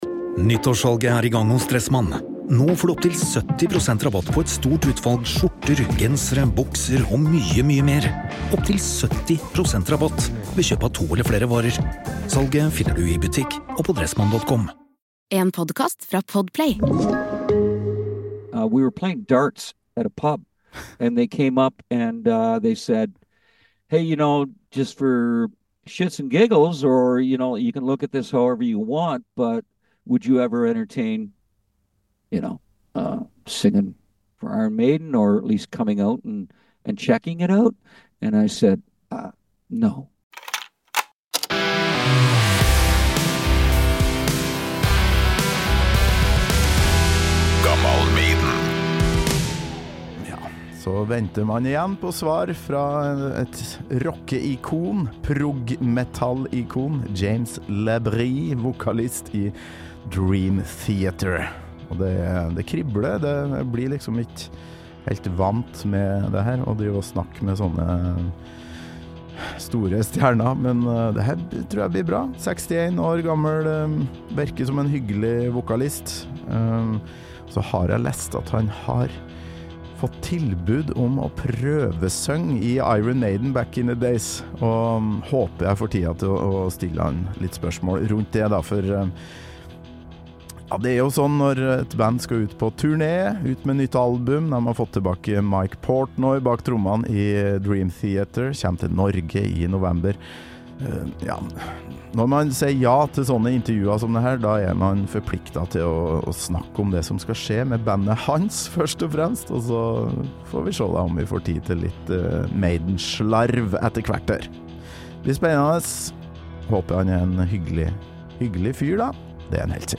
Norgesaktuelle Dream Theater er endelig gjenforent med trommis Mike Portnoy, feirer 40-årsjubileum med storturné og jobber med nytt album. Vokalist James LaBrie er gjest i Gammal Maiden og forteller villig vekk om forberedelsene til den kommende turnéen, ny musikk og hva slags musikk han er inspirert av.